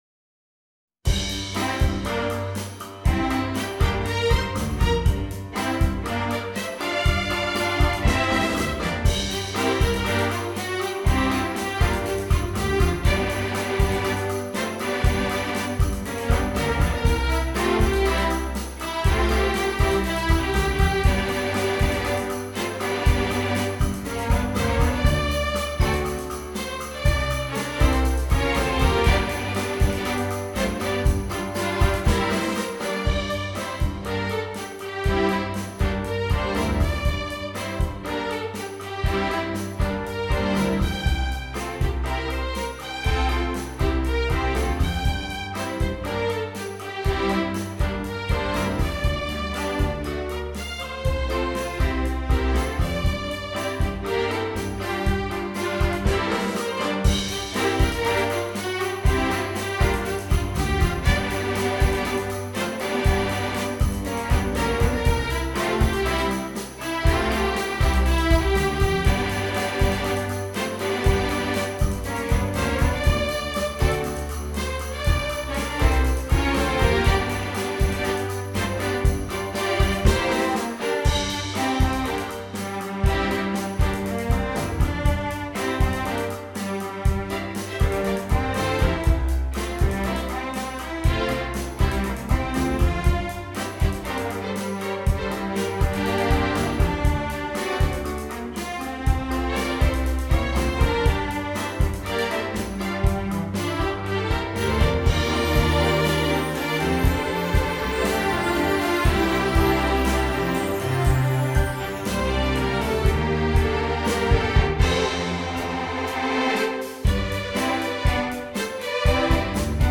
Orquestra de cordas